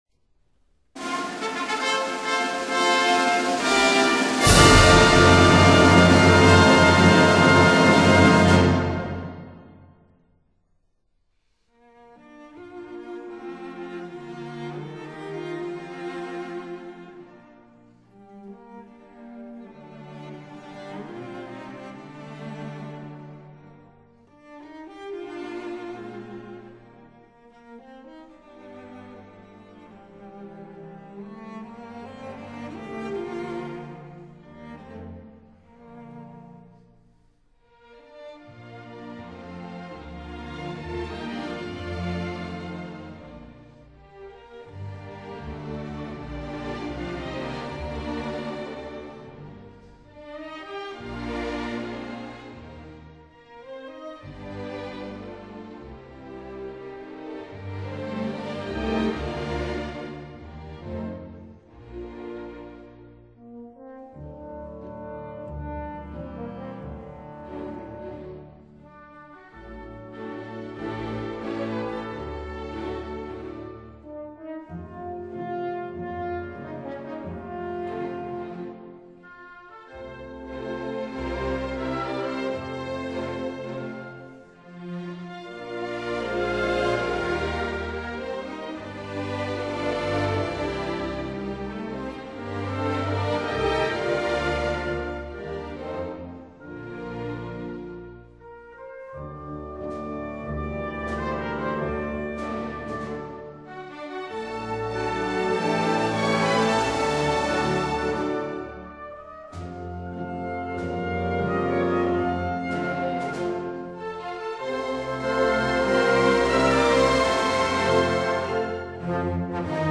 Period: Romantic